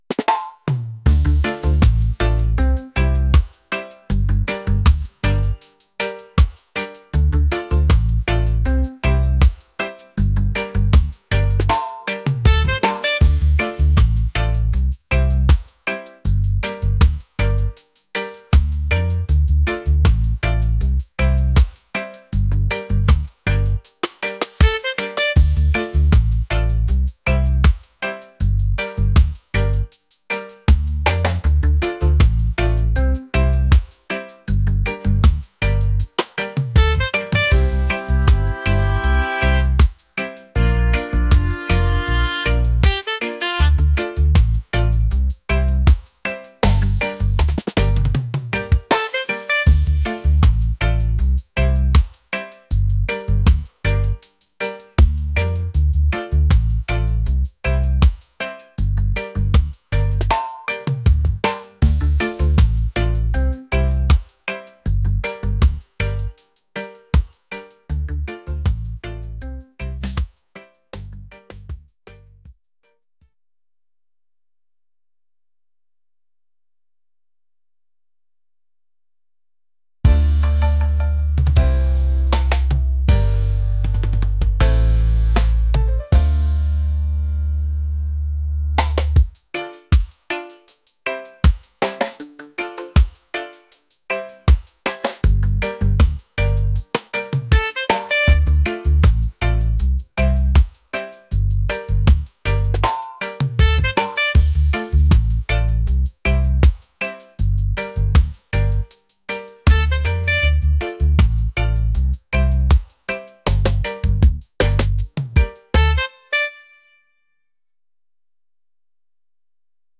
laid-back | reggae | vibes